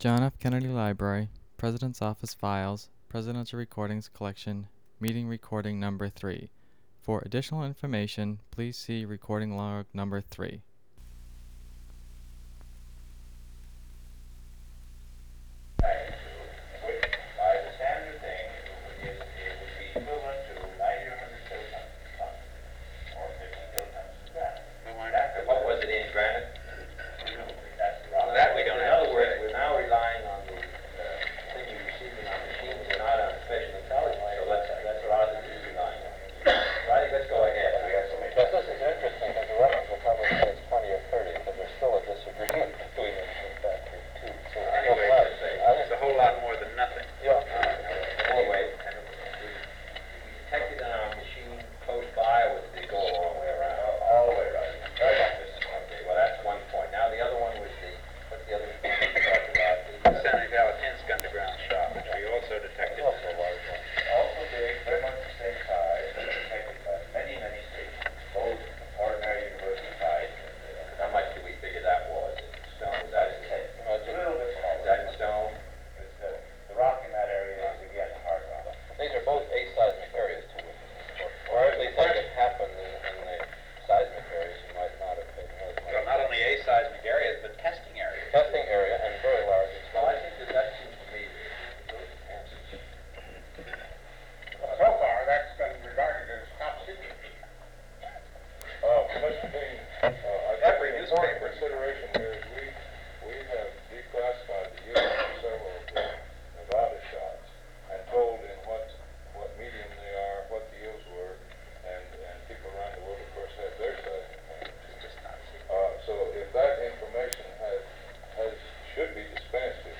Secret White House Tapes | John F. Kennedy Presidency Meeting on Nuclear Test Ban (cont.)